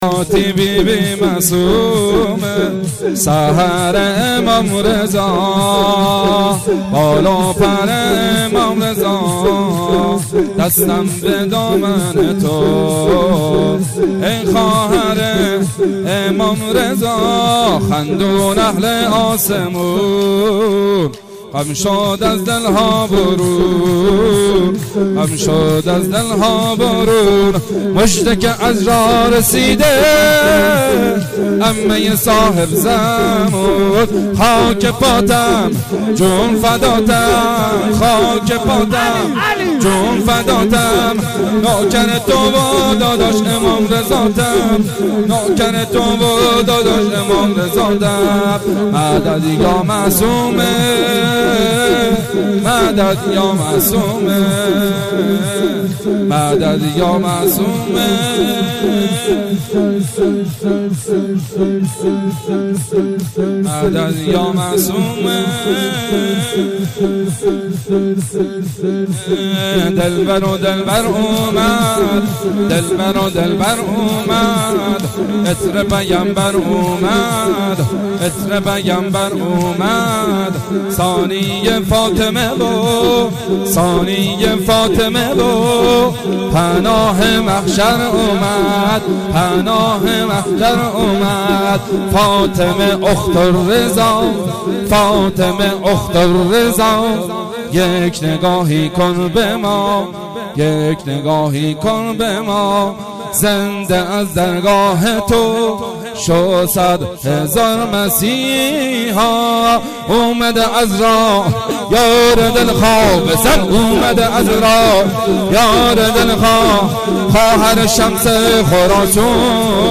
1 0 جشن به مناسبت دهه کرامت 1 0 جشن به مناسبت دهه کرامت 2 0 جشن به مناسبت دهه کرامت 1 0 جشن به مناسبت دهه کرامت 2 0 جشن به مناسبت دهه کرامت 1 0 جشن به مناسبت دهه کرامت 1 0 جشن به مناسبت دهه کرامت